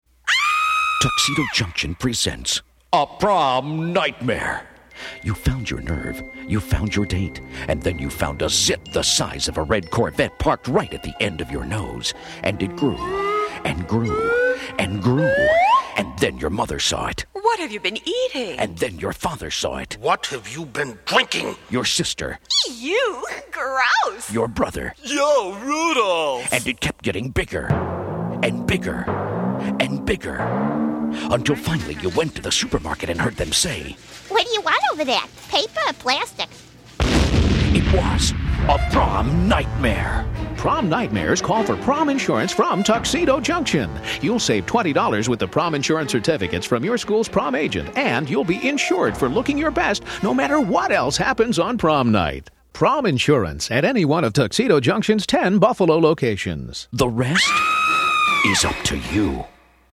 Through a series of four 60-second radio spots, we created a memorable campaign geared towards teens. Each spot featured a humorous "prom nightmare," encouraging kids to purchase prom insurance from Tuxedo Junction.